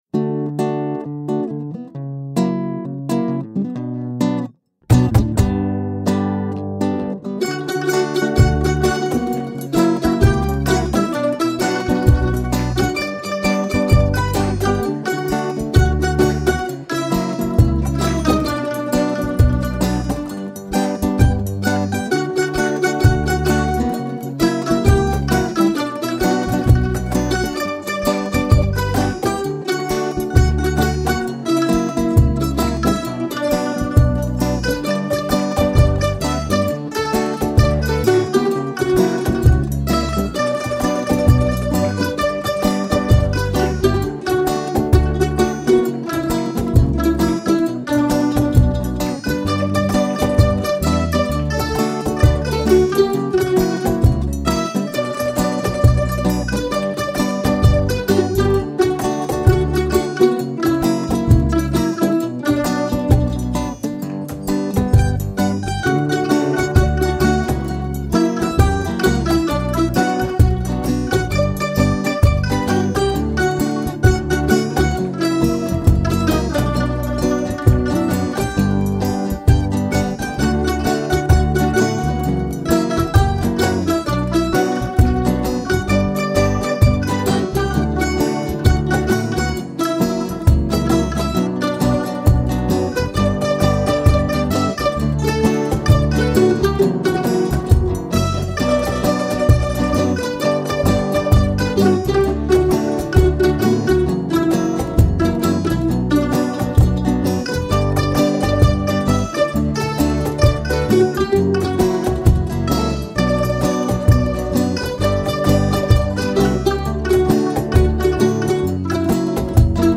1227   03:30:00   Faixa:     Xote